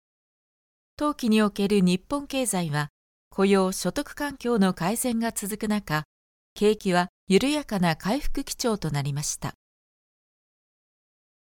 – Narration –
Newscaster